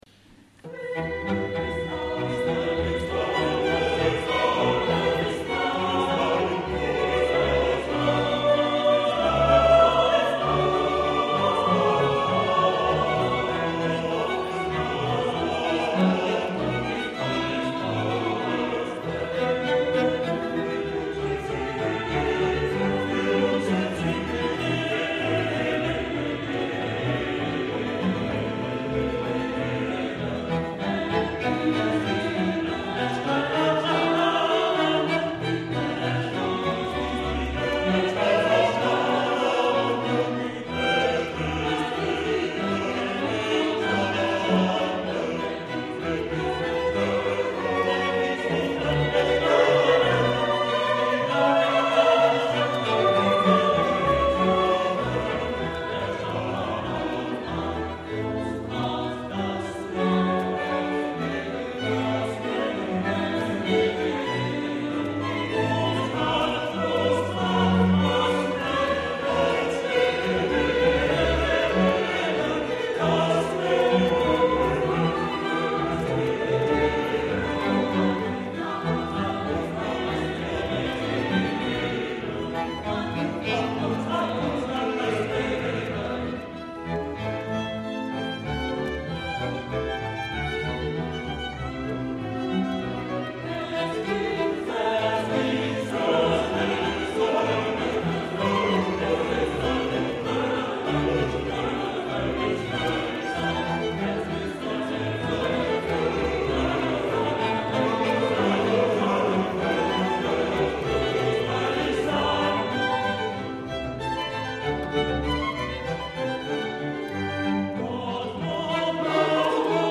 Cantates
Concerto pour deux violons
Ensemble Vocal Cum Jubilo
Violons
Alto
Violoncelle
Contrebasse
orgue et direction
Ecoutez un extrait de ce concert